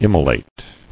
[im·mo·late]